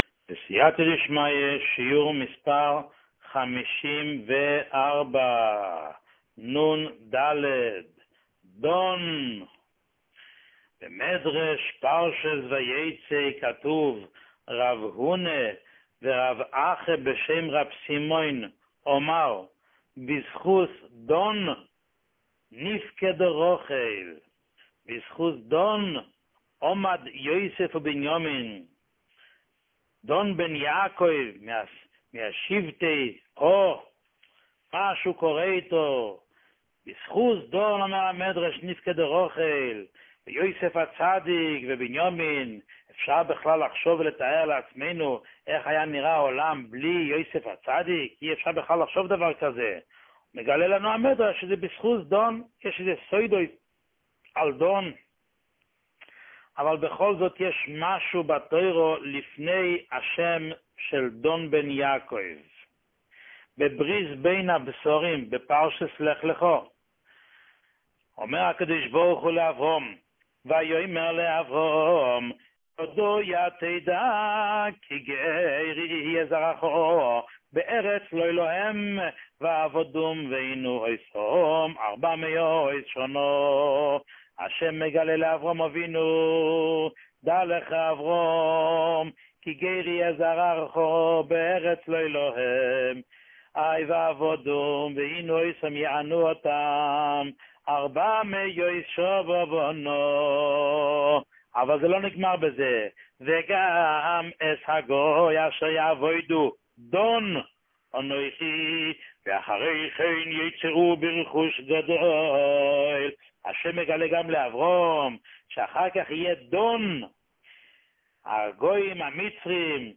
שיעור 54